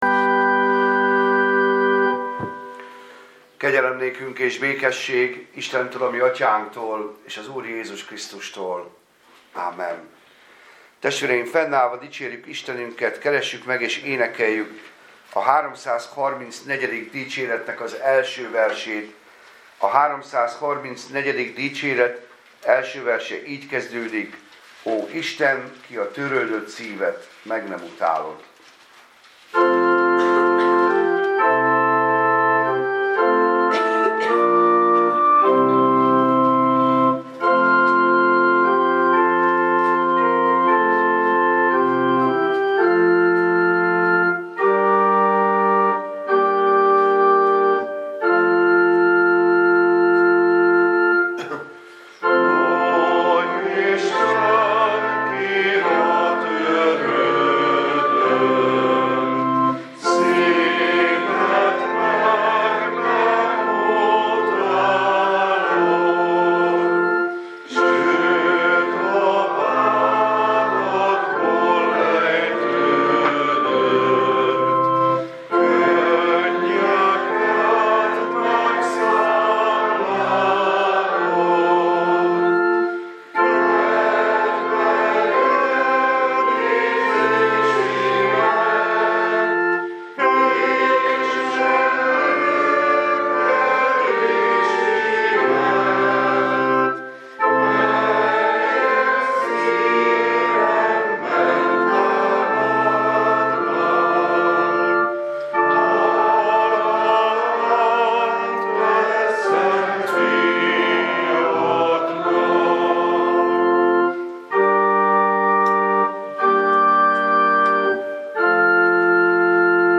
Passage: Lukács evangéliuma 23, 13-24 Service Type: Igehirdetés